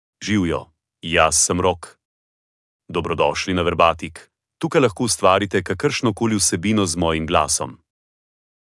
MaleSlovenian (Slovenia)
Rok — Male Slovenian AI voice
Rok is a male AI voice for Slovenian (Slovenia).
Voice sample
Rok delivers clear pronunciation with authentic Slovenia Slovenian intonation, making your content sound professionally produced.